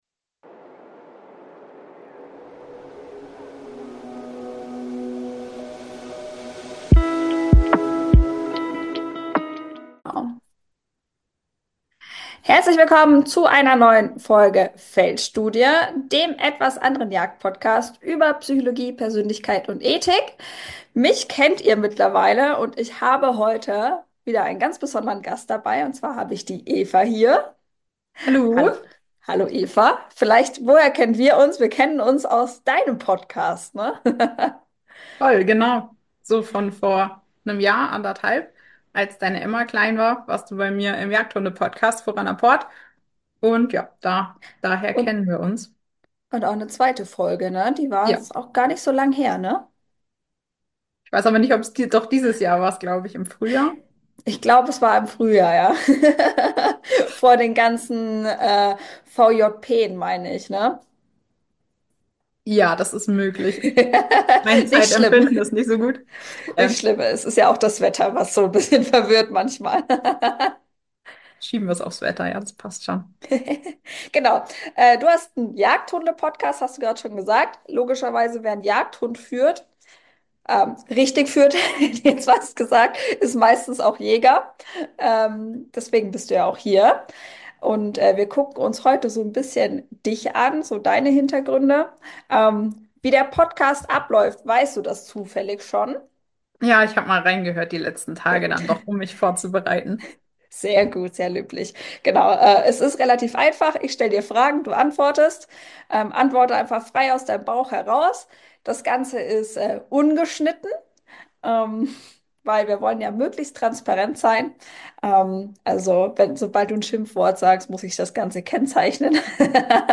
Ein Gespräch über Jagdfieber, Vierbeiner und die tolle Verbindung, die entsteht, wenn Mensch und Hund den Weg gemeinsam meistern.